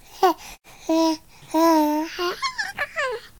giggle.ogg